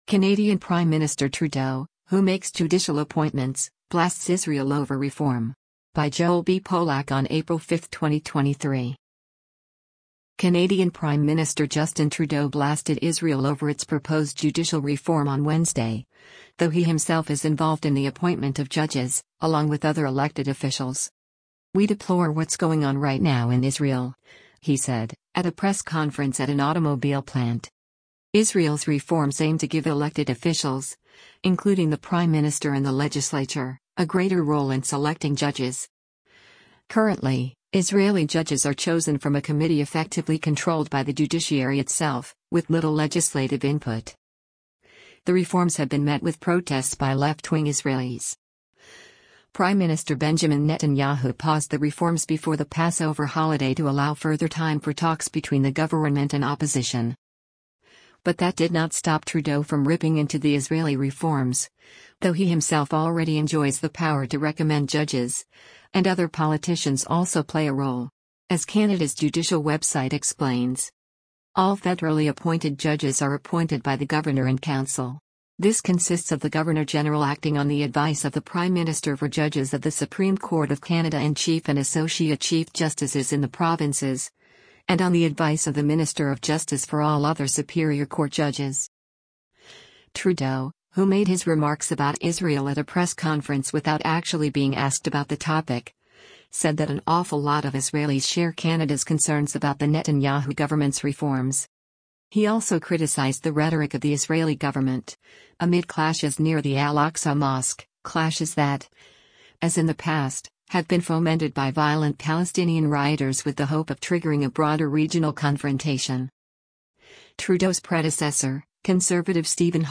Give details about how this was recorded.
“We deplore what’s going on right now in Israel,” he said, at a press conference at an automobile plant.